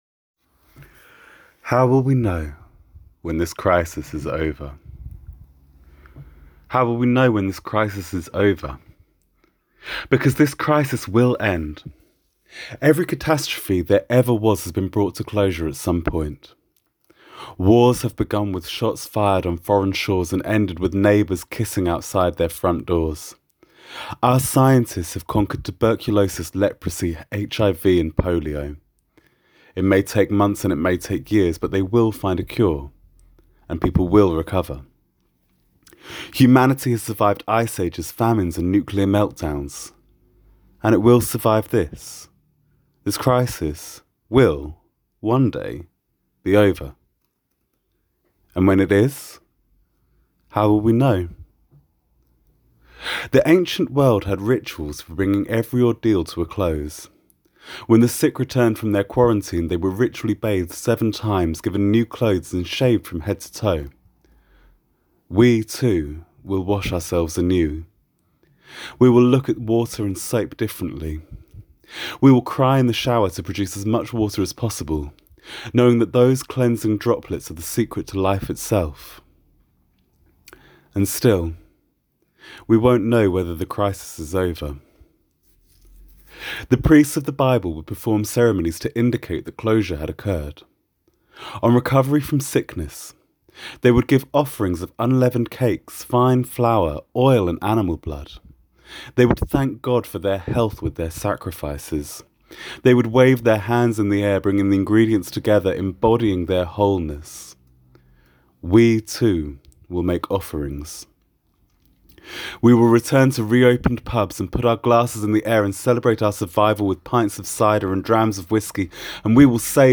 judaism · sermon · theology